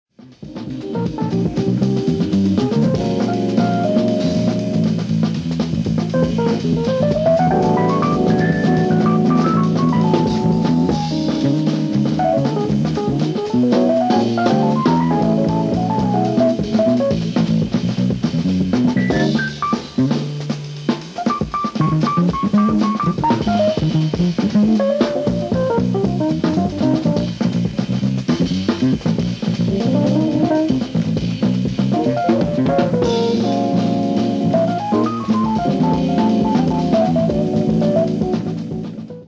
LIVE AT ULTRASONIC STUDIO, HAMPSTEAD, NY 10/01/1973
SOUNDBOARD RECORDING